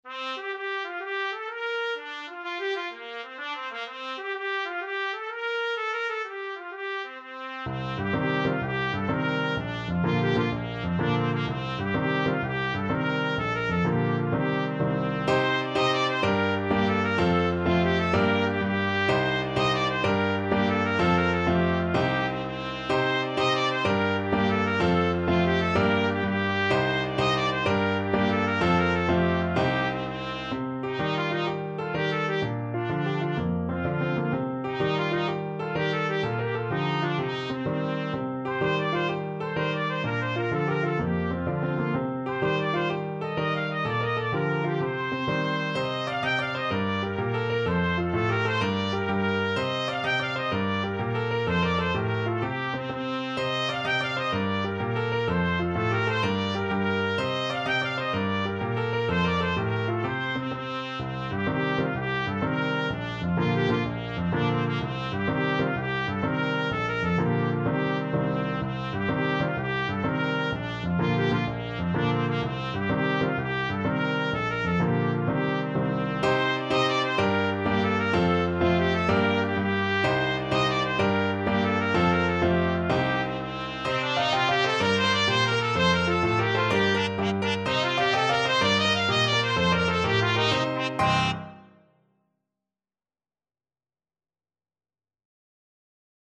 Trumpet
G minor (Sounding Pitch) A minor (Trumpet in Bb) (View more G minor Music for Trumpet )
Fast .=c.126
12/8 (View more 12/8 Music)
Irish